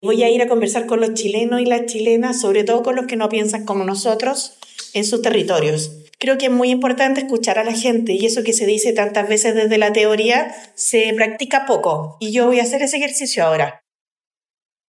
La candidata afirmó este martes, en el marco del XXI Seminario de Moneda Patria Investments 2025, que su campaña entra en una “ruta distinta”.